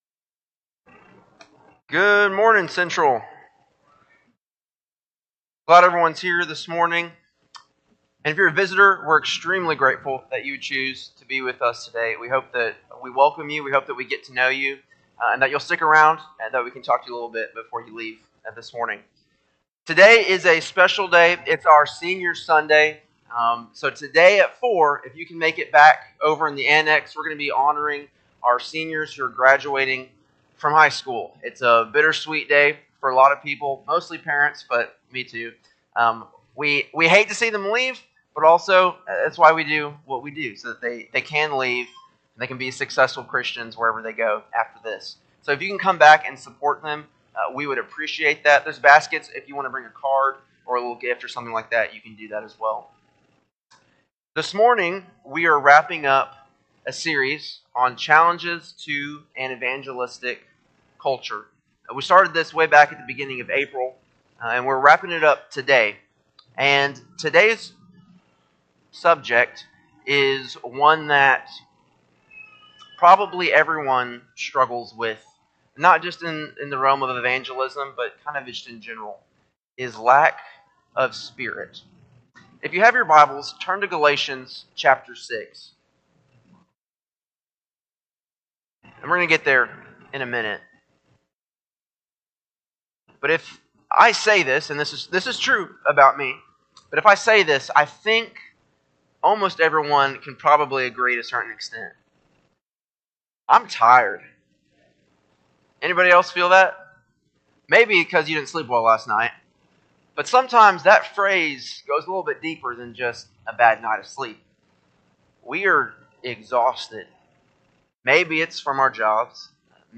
5-4-25-Sunday-AM-Sermon.mp3